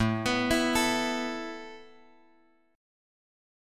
A Suspended 2nd